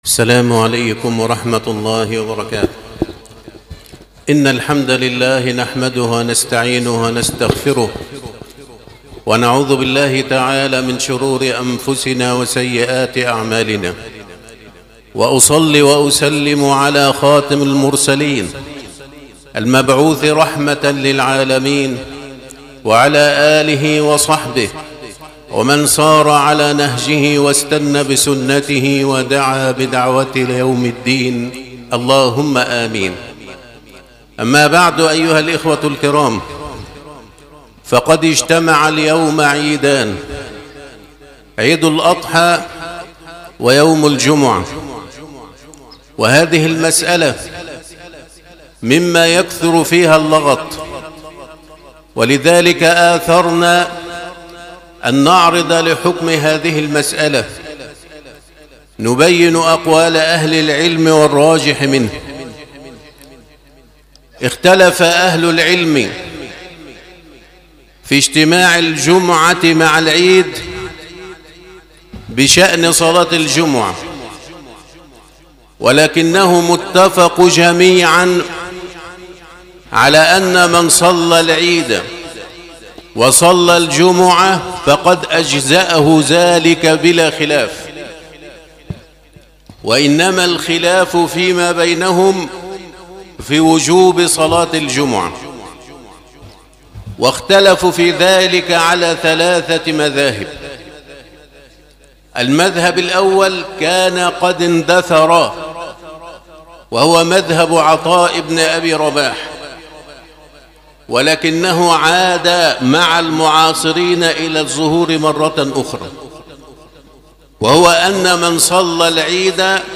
خطبة عيد الأضحى 1446بعنوان " اجتماع الجمعة والعيد" - موقع هدي الله